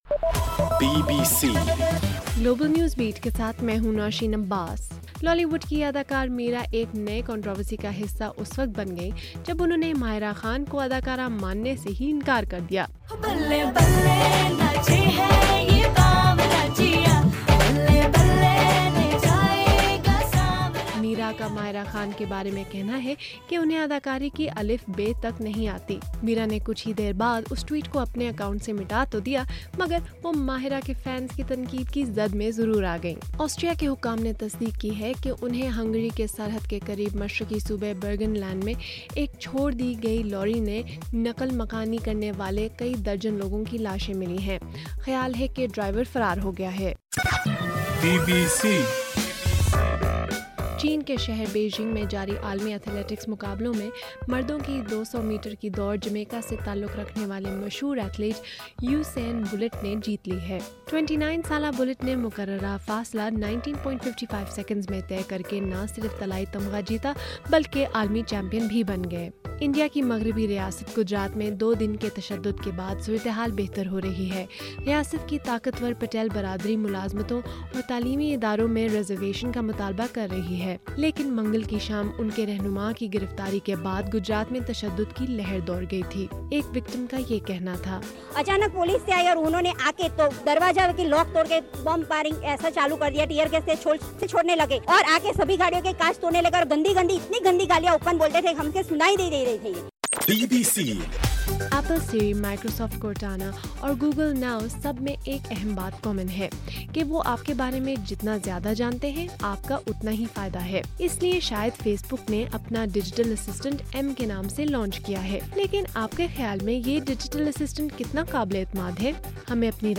اگست 27: رات 10 بجے کا گلوبل نیوز بیٹ بُلیٹن